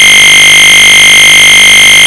Celebrate the conkering of ANGELFACE or EINSTEIN with a sound effect.
conker.ogg